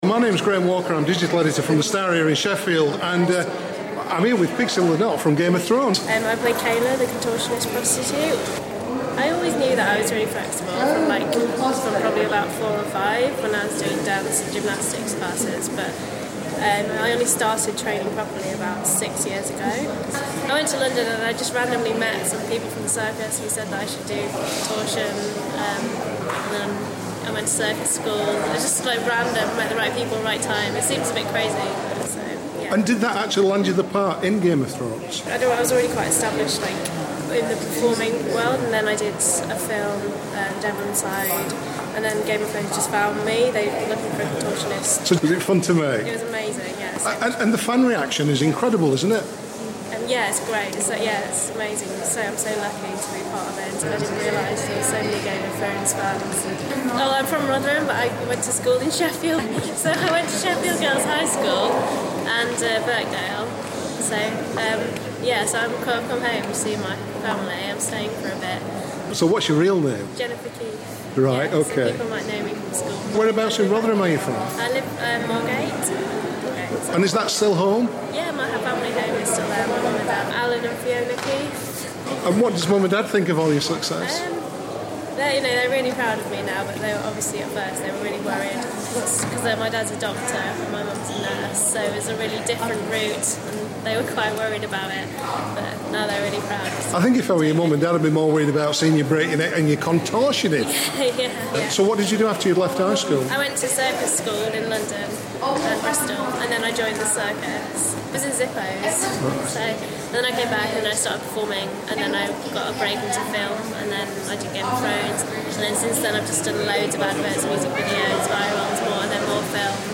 Interview
Hear how she got the job on the hit TV show and her other upcoming film project. We chatted at Sheffield Film and Comic Con 2014, at the Motorpoint Arena.